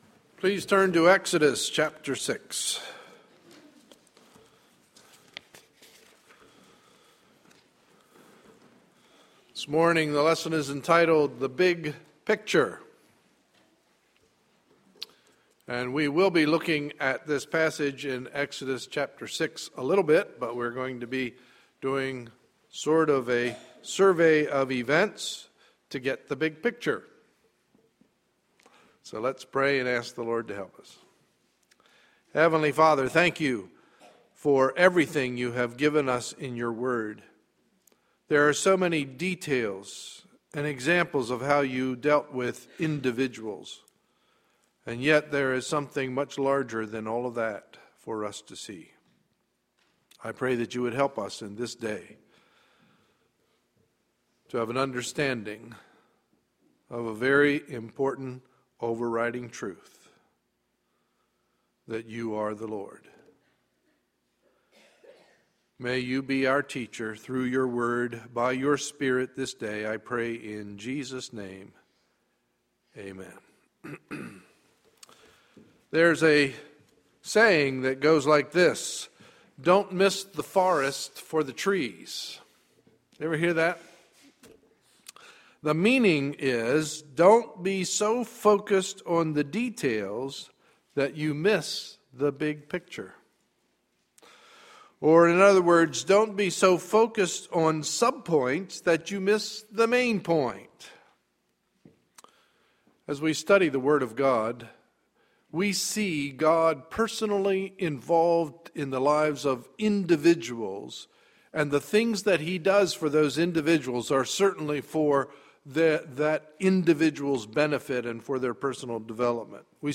Sunday, March 3, 2013 – Morning Message